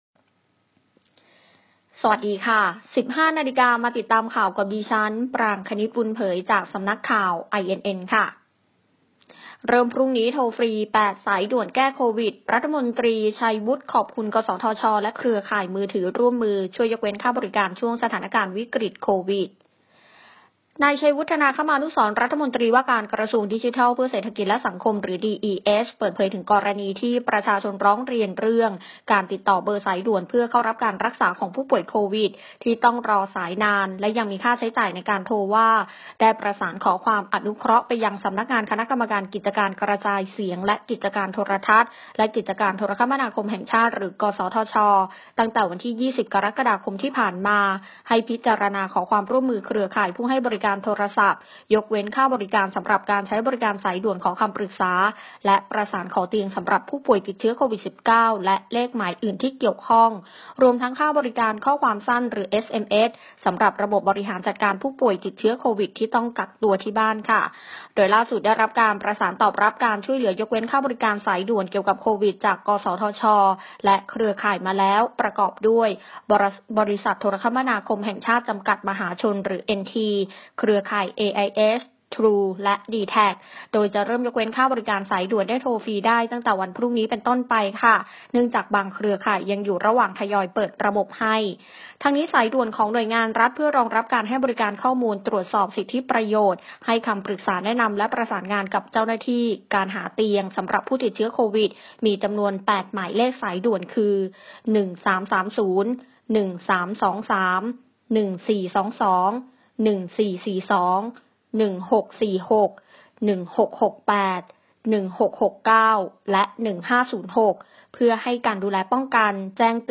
ข่าวต้นชั่วโมง 15.00 น.